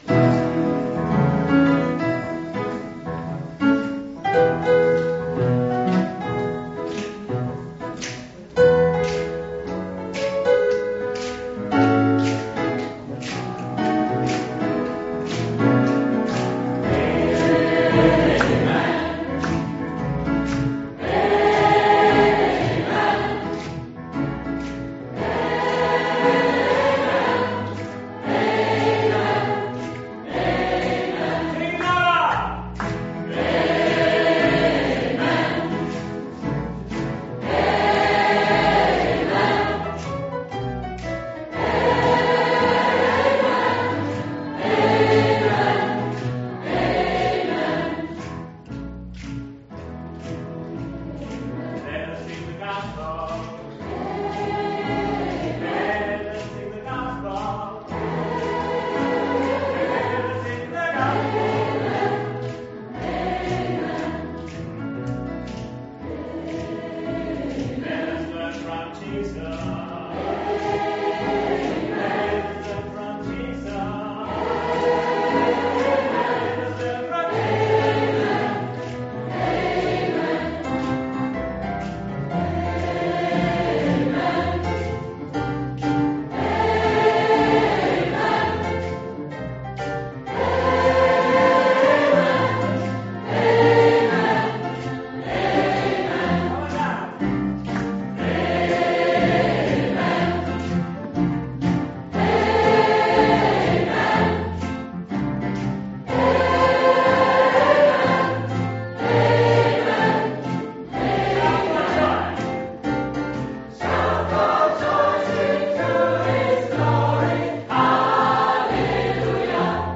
Gospelchor der Gnadenkirche Wien
Amen - Aufnahme aus der Gnadenkirche, Juni 2016 (1,76 mb)